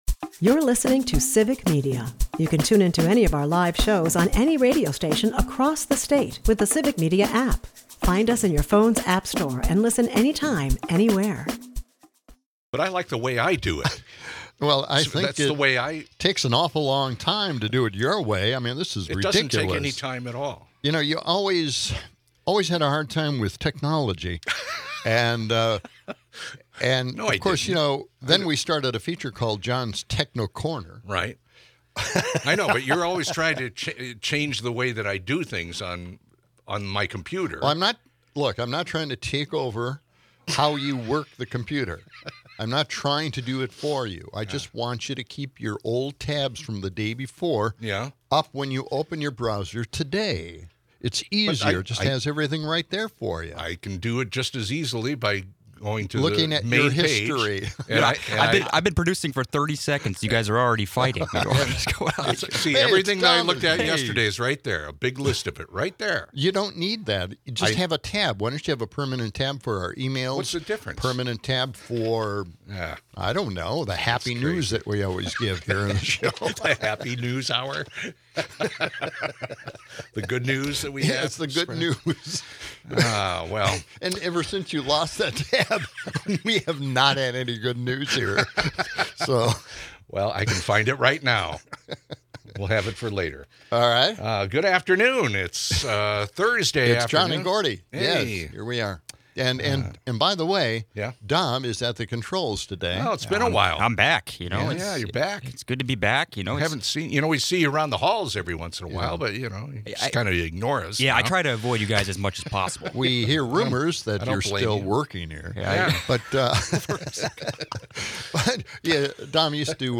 Towards the end of the hour, Trump's obliviousness at Davos is lampooned, with mix-ups between Iceland and Greenland adding to the hilarity. Heated debates ensue over U.S. politics, with calls for Democrats to show a clearer agenda. Listeners chime in, critiquing local politicians and questioning if Democrats indeed control the weather. High-tech gadgets and their quirks also make a cameo.